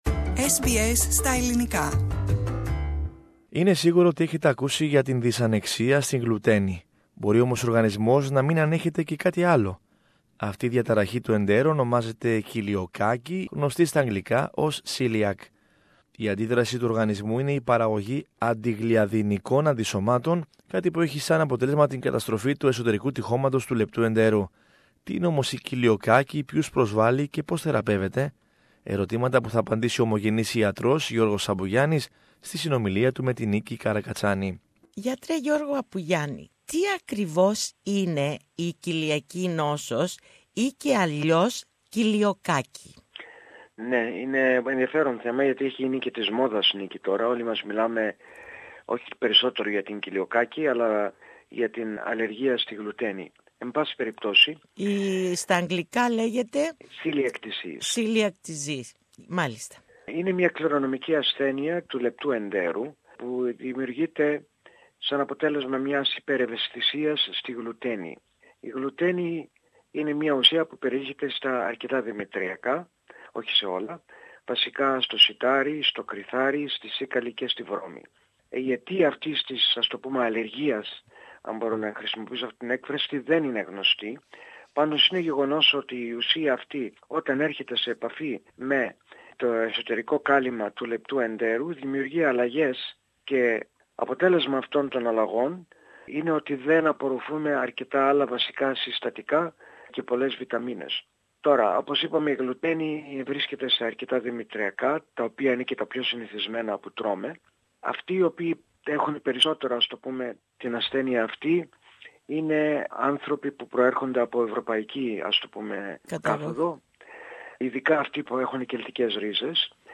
Medical report